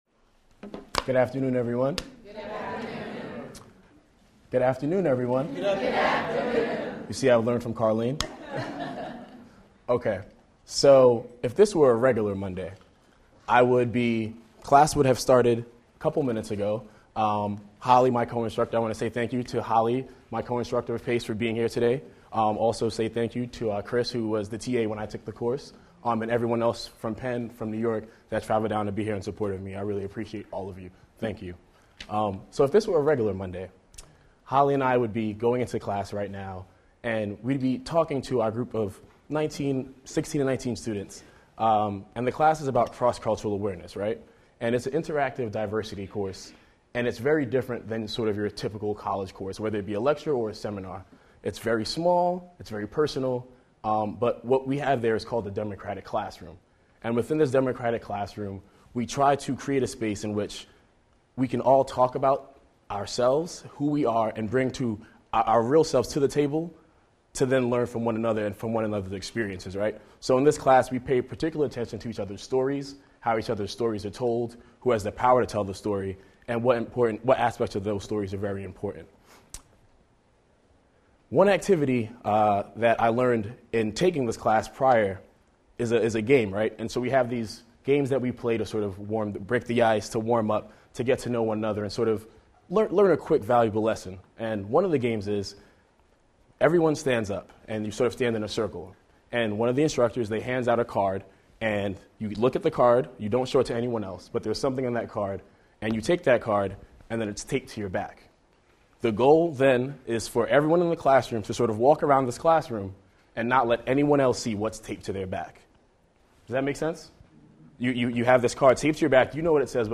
keynote address